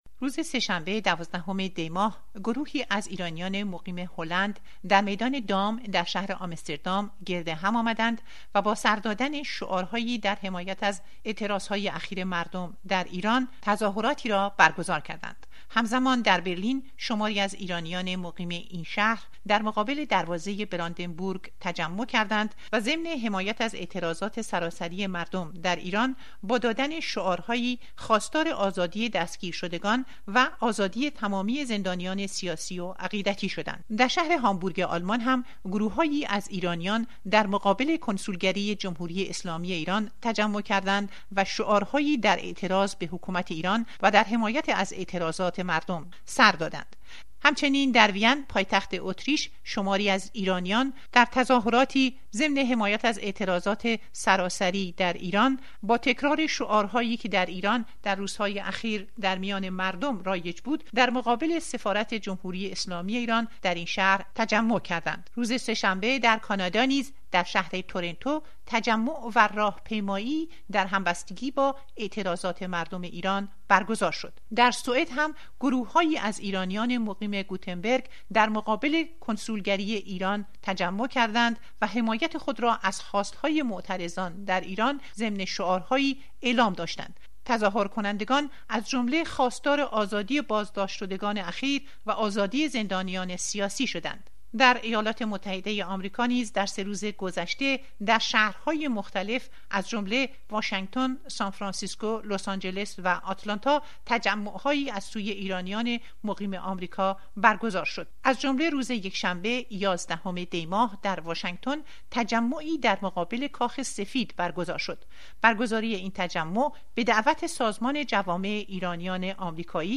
شهرهای مختلف جهان از جمله برلین، بخارست، هامبورگ، آنکارا،آمستردام،کپنهاگ،واشینگتن و تورنتو، روزهای سه شنبه و چهارشنبه،شاهد تظاهرات در حمایت از اعتراضات در ایران بودند. گزارش رادیو فردا را بشنوید: